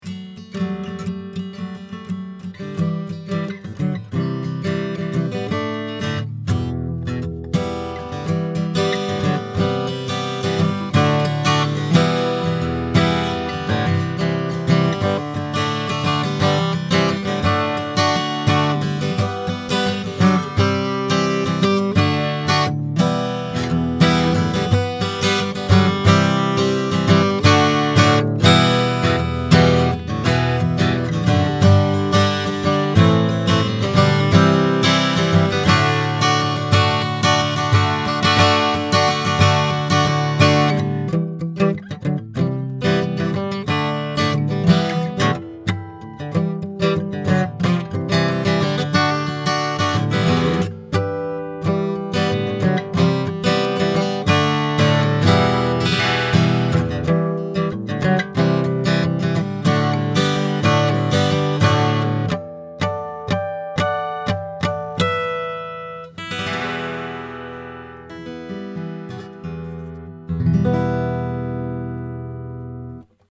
It has bass roll-off to compensate for proximity effect, and a slight HF bump.
Here are a few quick, 1-take MP3 sound files to give you an idea of what to expect. These MP3 files have no compression, EQ or reverb -- just straight signal, tracked into a Presonus ADL 600 preamp to an Apogee Rosetta 200 A/D converter into Logic.
SANTA CRUZ OM/PW AC. GUITAR